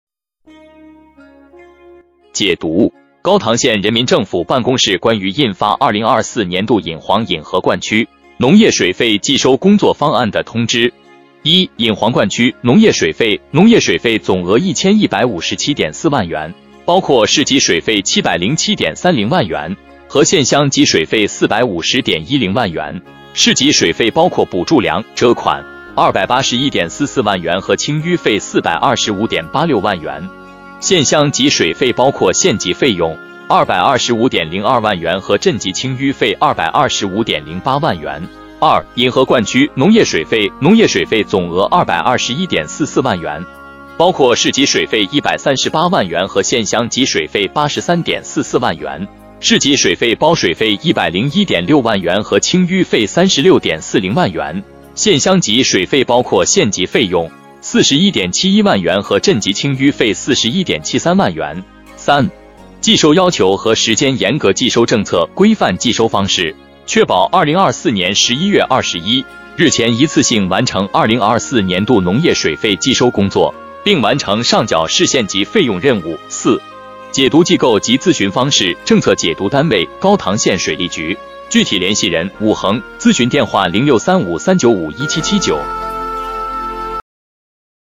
音频解读：高唐县人民政府办公室关于印发2024年度引黄引河灌区农业水费计收工作方案的通知